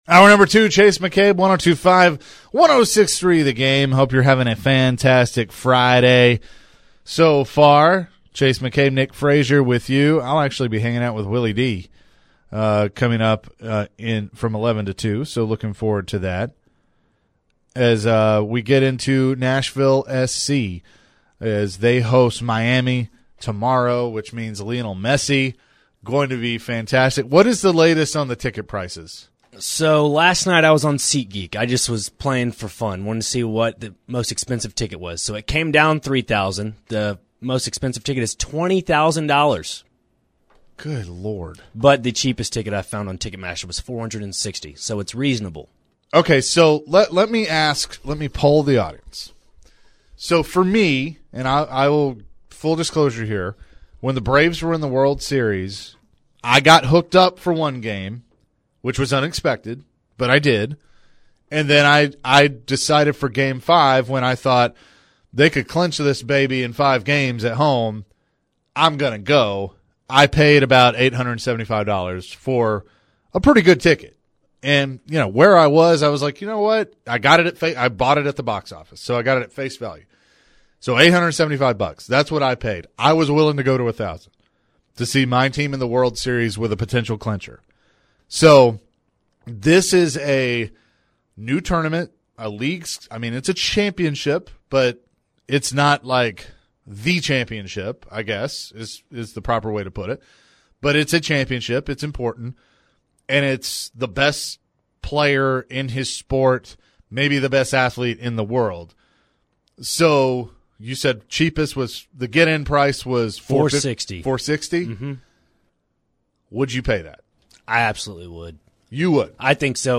talks global sports figures by taking your calls and texts. Who are the most universally well-known athletes of all time?